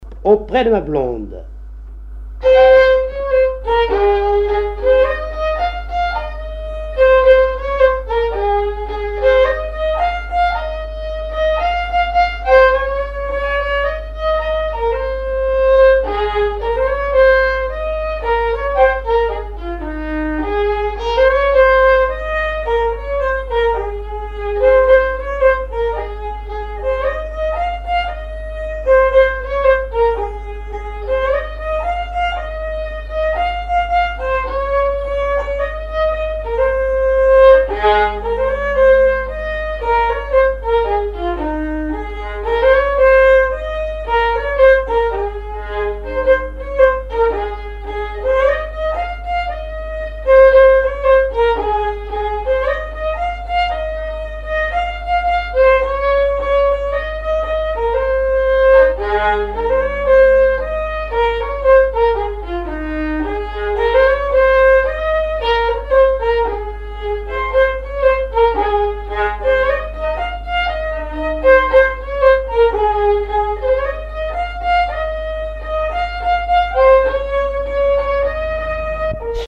Fonction d'après l'analyste gestuel : à marcher
Genre laisse
Pièce musicale inédite